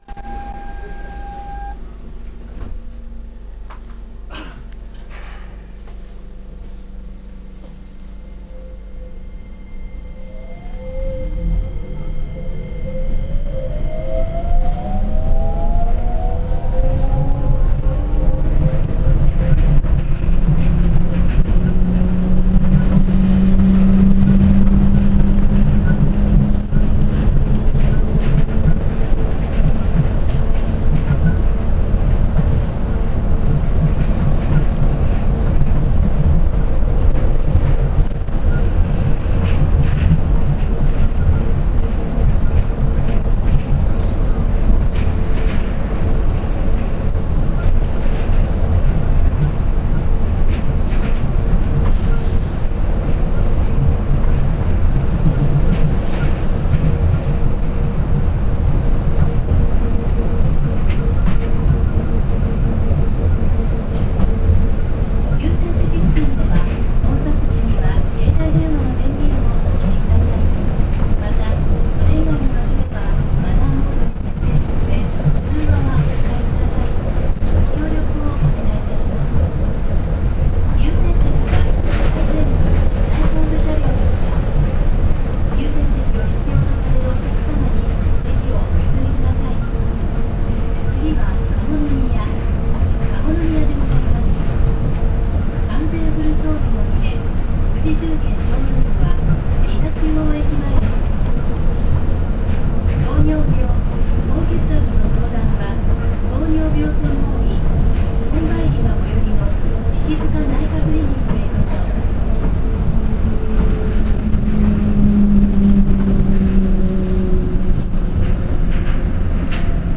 ・2000系走行音
【伊奈線】鉄道博物館(大成)→加茂宮（2分28秒：809KB）
走行音自体はよくある東洋IGBTでこれと言って特徴はありません。ドアチャイムは他所では聞いた事がない物を使用しており、ブザーとチャイムの合間と言える音が流れます。自動放送も勿論搭載していますが、この放送は駅到着時に到着放送としても車外スピーカーから流れます。これはニューシャトルの大きな特徴の1つ。